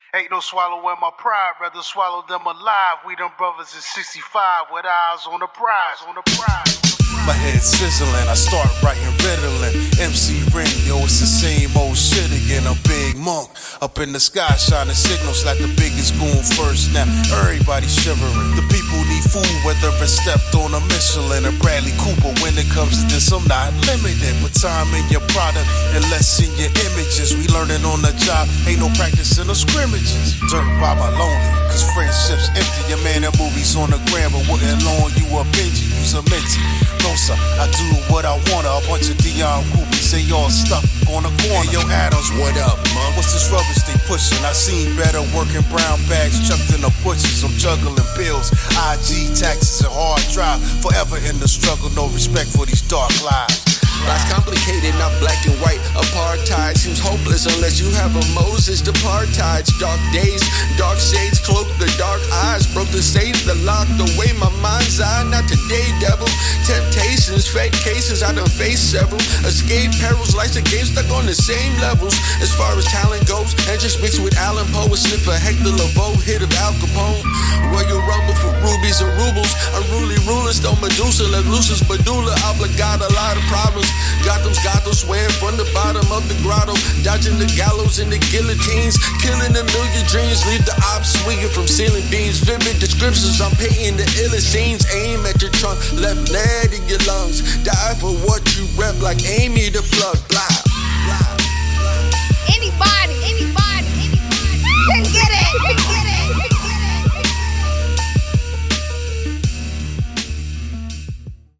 Backed by a hard rhythm